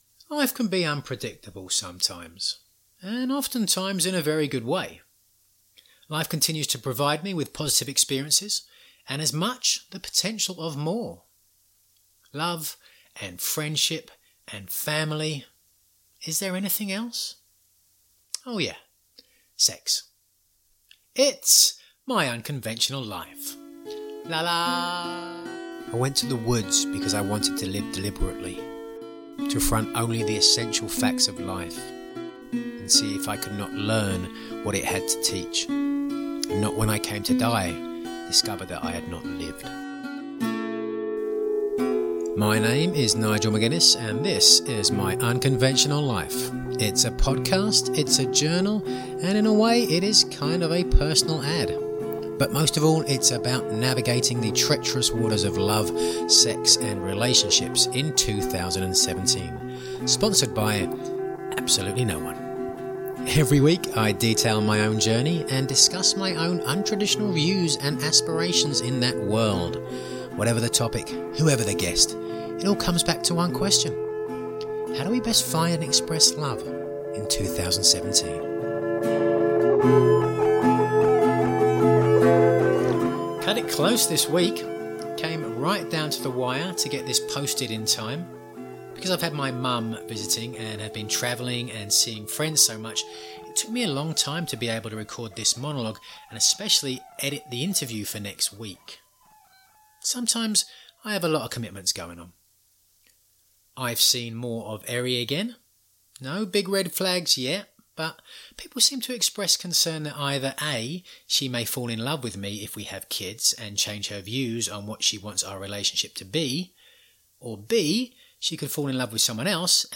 Monologue